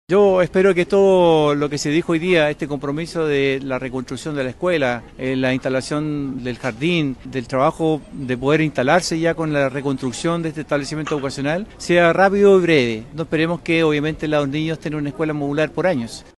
El alcalde de Tomé, Italo Cáceres, agradeció la celeridad para dar continuidad a un tema tan importante como la educación, esperando -dijo- que las próximas autoridades continúen y terminen la reposición del establecimiento.